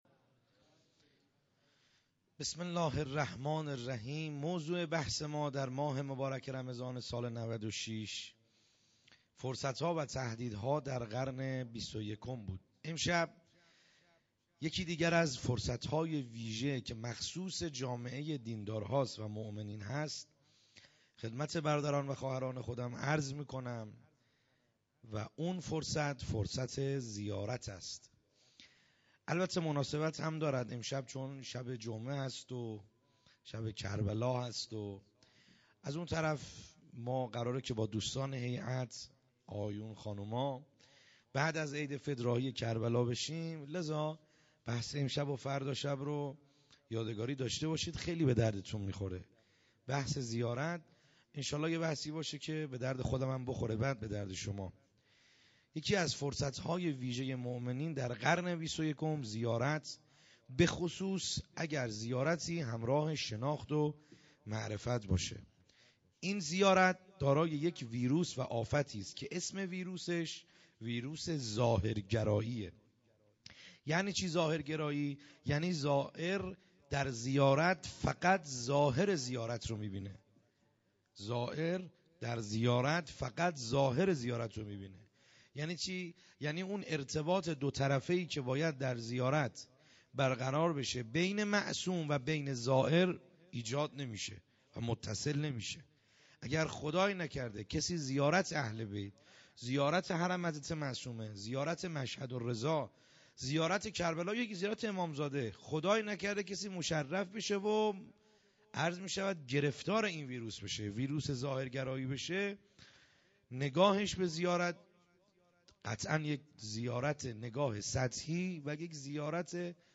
خیمه گاه - بیرق معظم محبین حضرت صاحب الزمان(عج) - سخنرانی